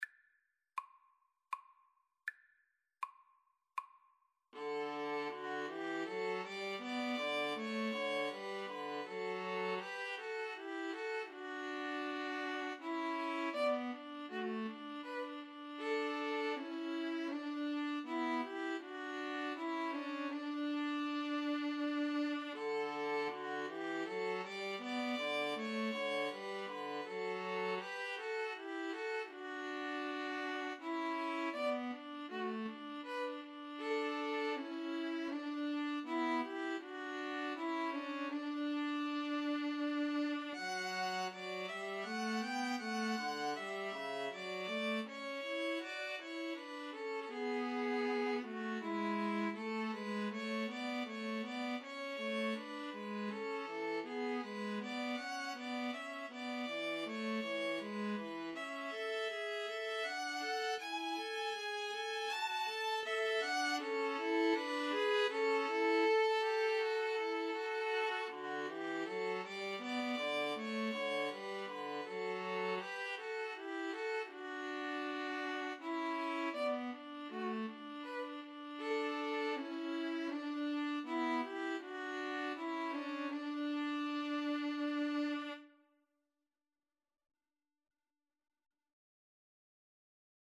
D major (Sounding Pitch) (View more D major Music for String trio )
Andante
String trio  (View more Easy String trio Music)
Classical (View more Classical String trio Music)